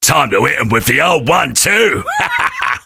sam_start_vo_01.ogg